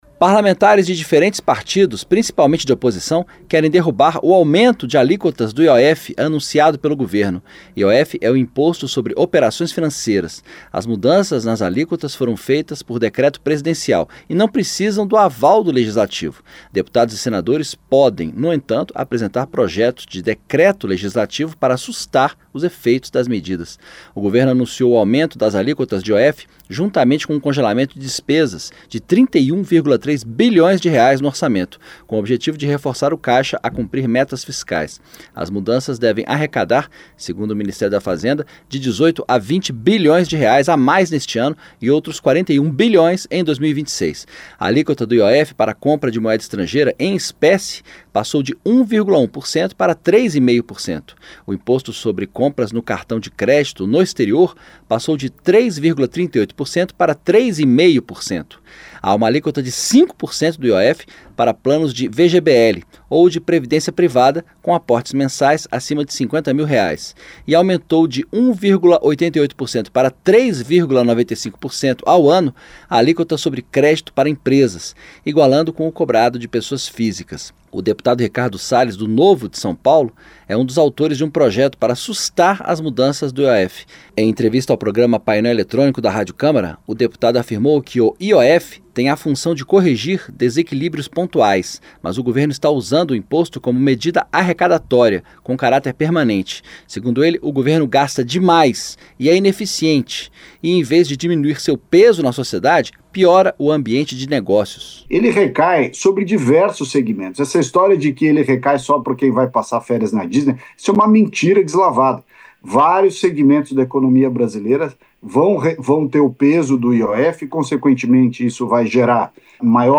DEPUTADOS DISCORDAM SOBRE FUTURO DE PROJETOS QUE SUSTAM AUMENTO DO IOF. ENTENDA NA REPORTAGEM